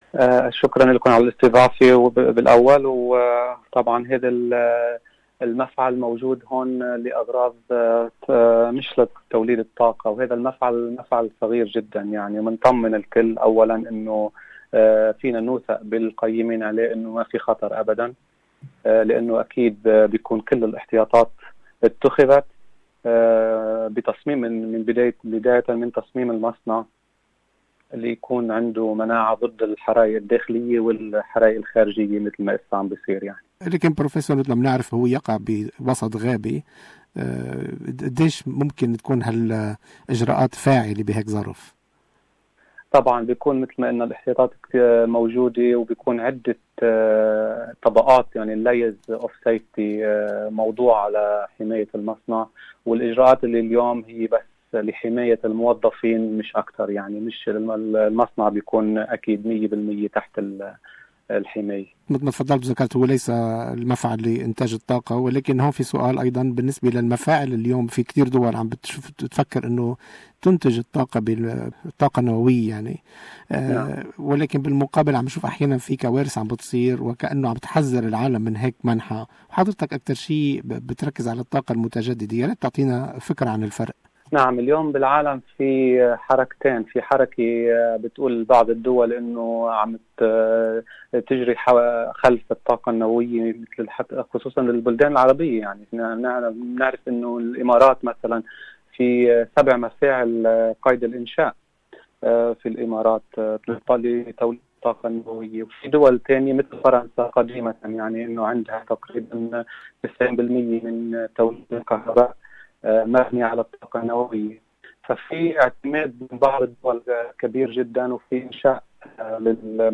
المزيد في هذه المقابلة.